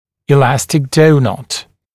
[ɪ’læstɪk ‘dəunʌt][и’лэстик ‘доунат]эластичный сепаратор (эластичный «пончик»)